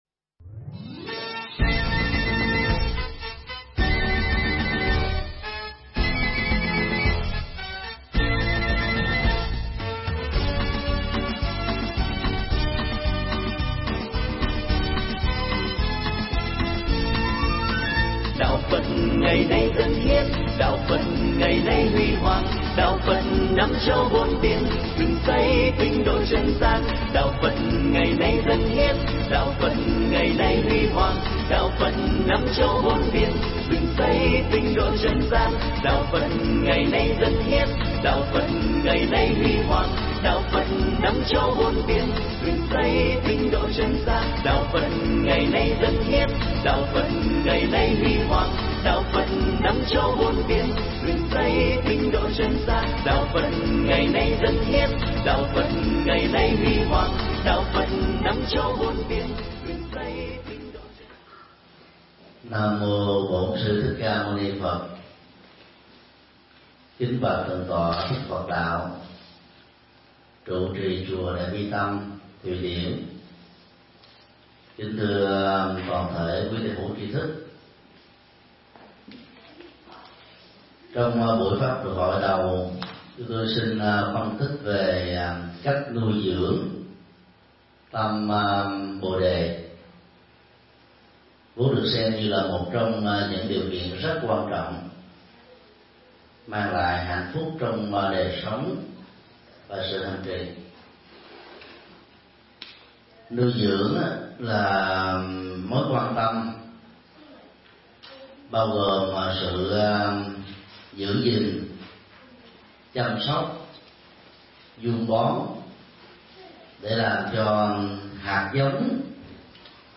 Nghe mp3 pháp thoại Nuôi dưỡng tâm bồ-đề 1
giảng tại chùa Đại Bi Tâm, Thụy Điển